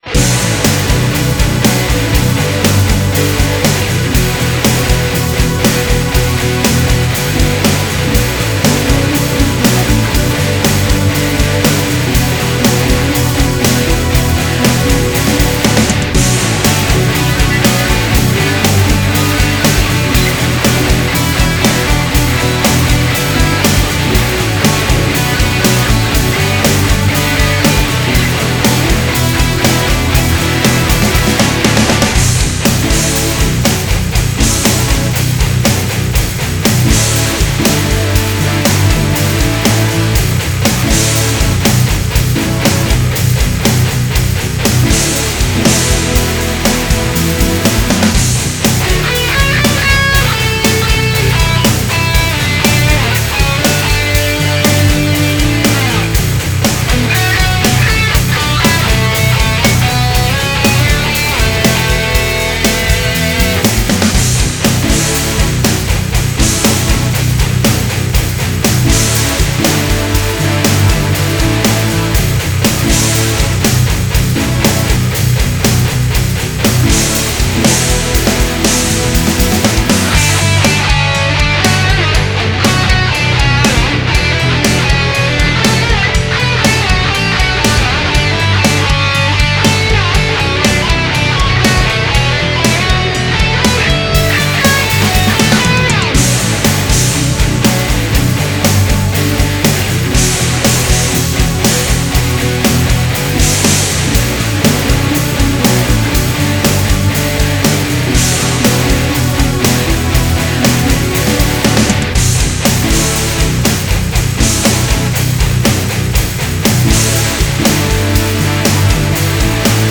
(strumentale)